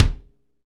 KIK FNK K00L.wav